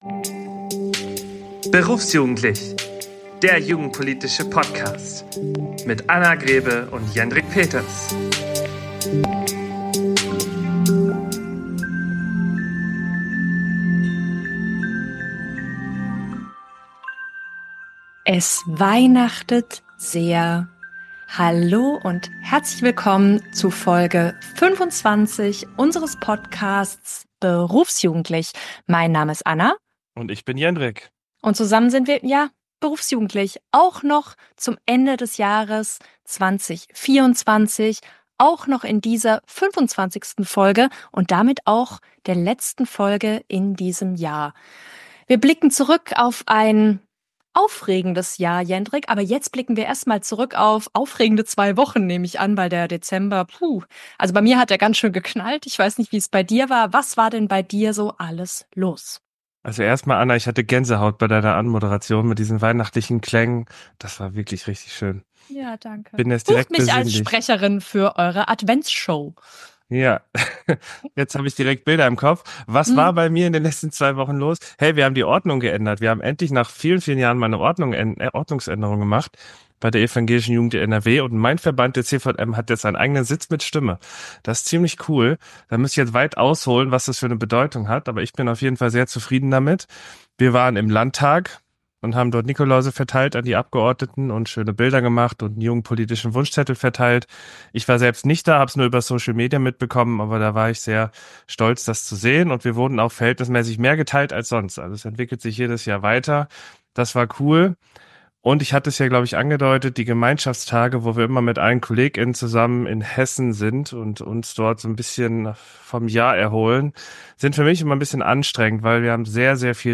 Ganze 15 O-Töne von euch Hörer:innen sind in dieser Folge verbaut, viel Spaß beim Hören!